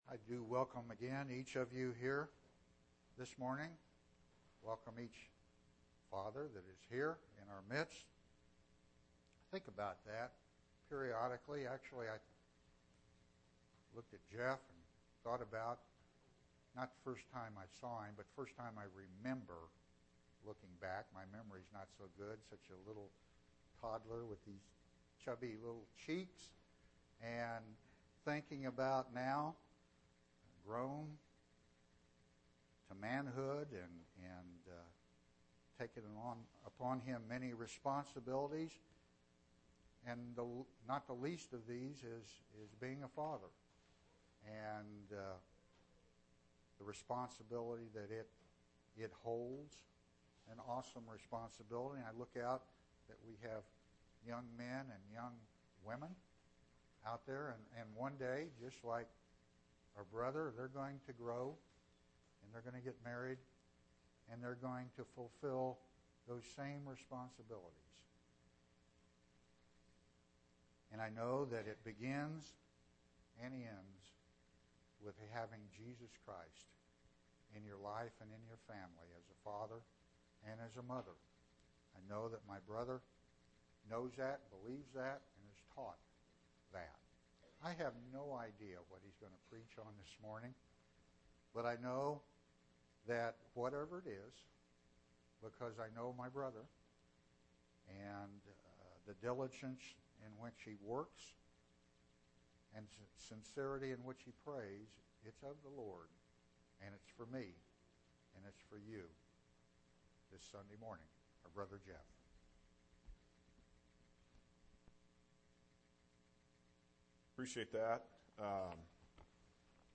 6/15/2014 Location: East Independence Local Event